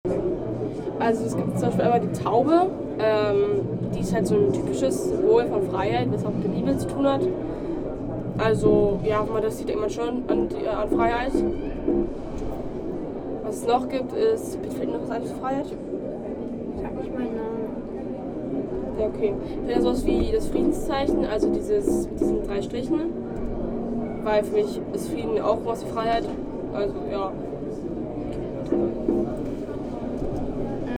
Standort der Erzählbox:
Stendal 89/90 @ Stendal
Standort war das Stendal.